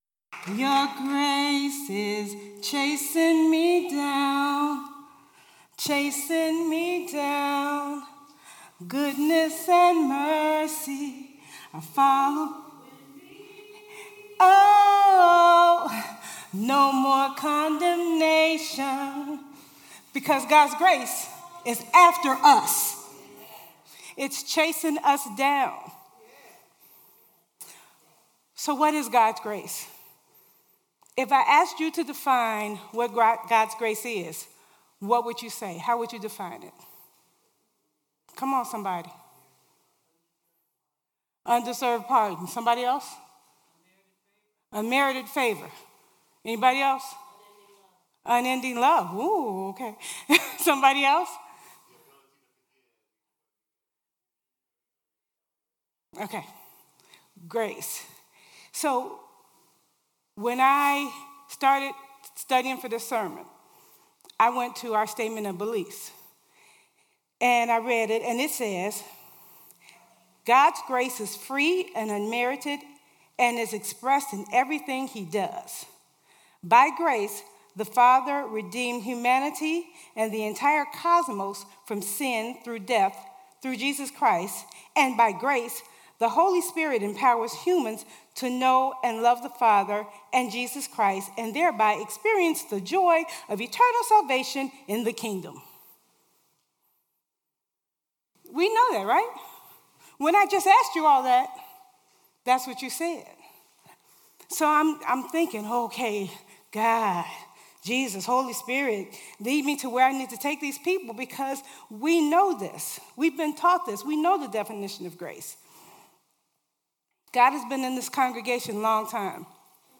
Worship Service 5/20/18